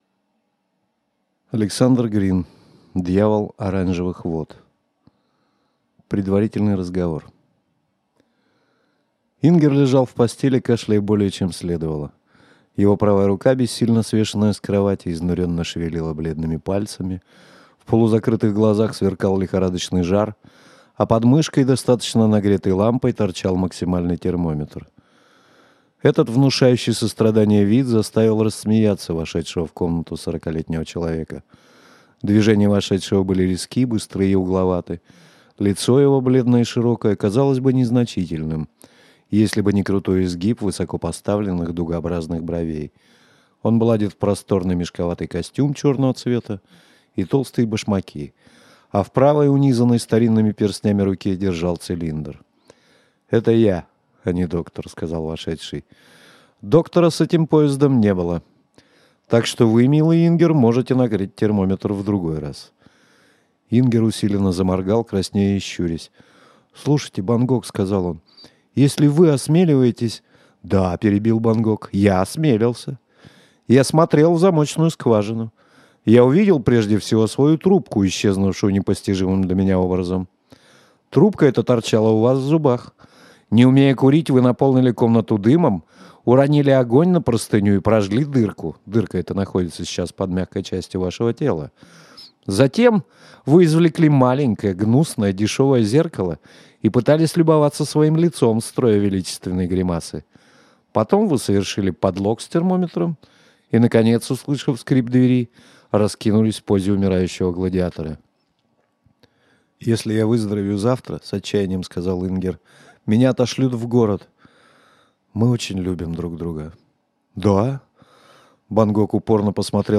Аудиокнига Дьявол Оранжевых Вод | Библиотека аудиокниг